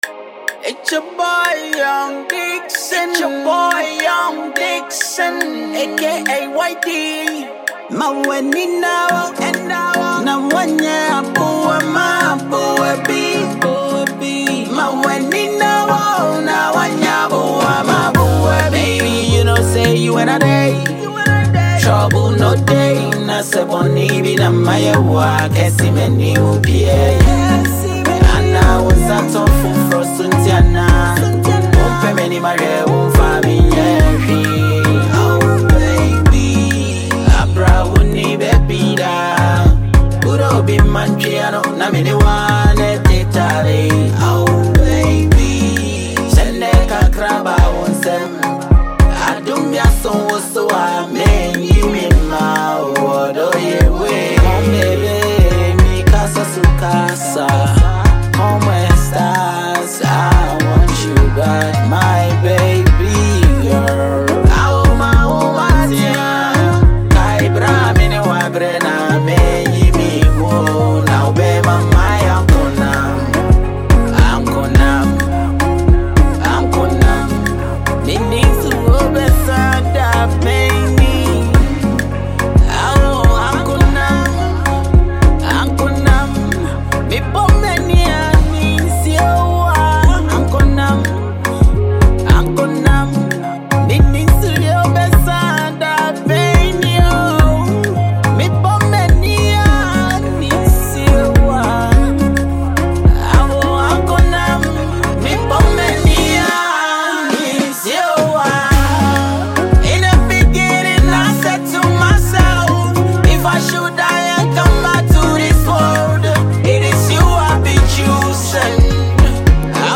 Highly-rated Ghanaian rapper and singer